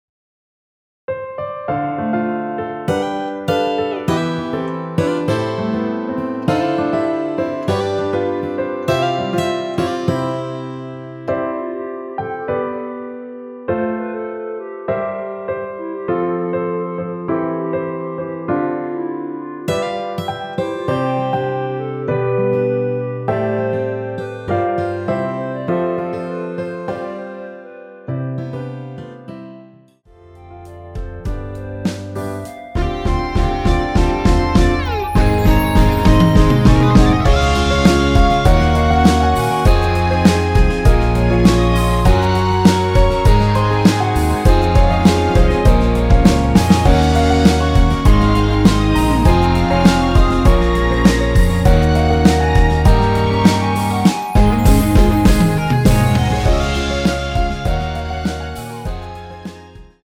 원키에서(+3)올린 멜로디 포함된 MR입니다.(미리듣기 확인)
앞부분30초, 뒷부분30초씩 편집해서 올려 드리고 있습니다.
중간에 음이 끈어지고 다시 나오는 이유는